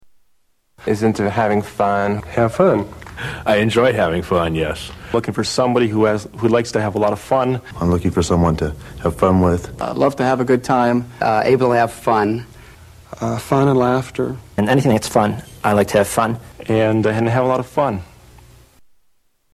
Tags: Media Funny Video Dating Guys Video Dating Guys Video Dating Interview 1980's Video Dating